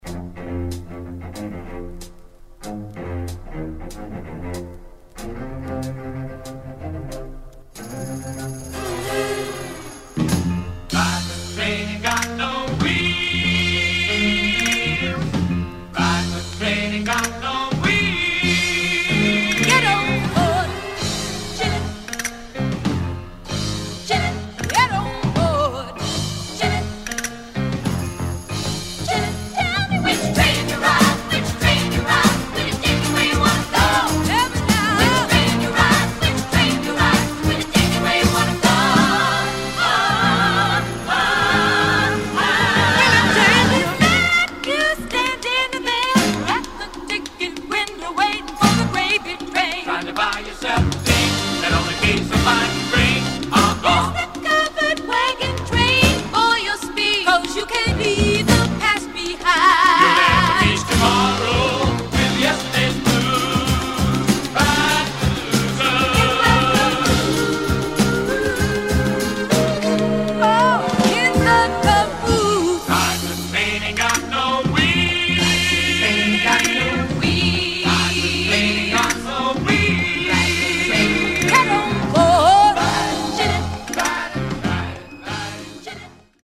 Obscure multi-cultural gospel sextet from the west-coast.